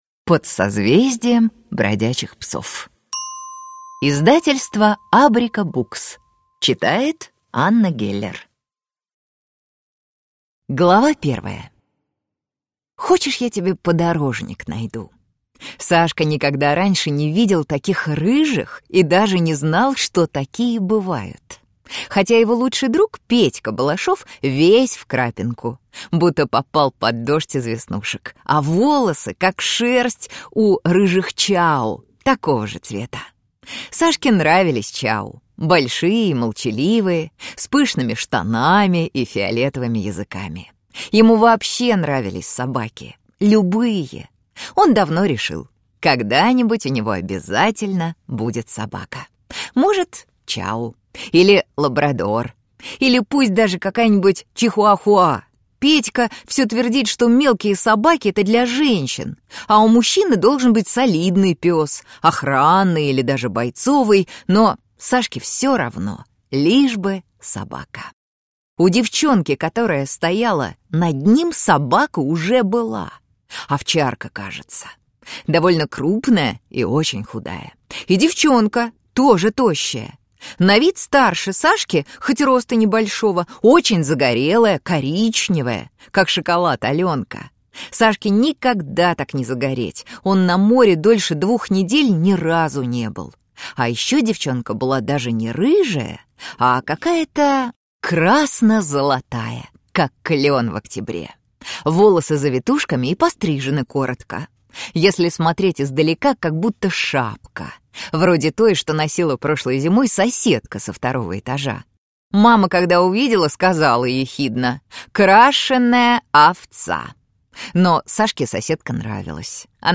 Аудиокнига Под созвездием Бродячих Псов | Библиотека аудиокниг